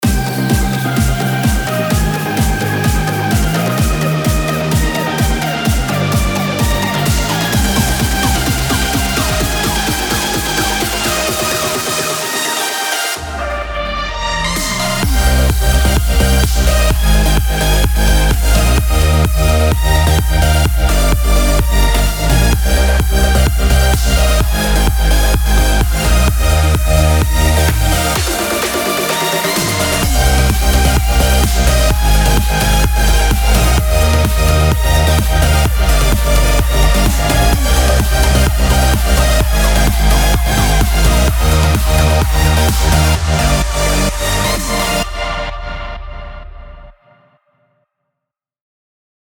וואו זה ממש כיפי ואנרגטי, אישית אני לא היית בוחר בסאונד שבחרת עבור המלודיה, הייתי מעדיף Pluck, משהו יותר עמוק ופחות מלוכלך.